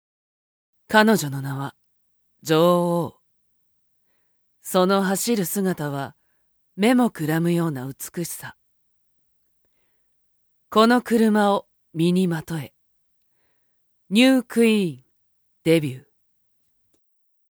◆新車CM（クール系）◆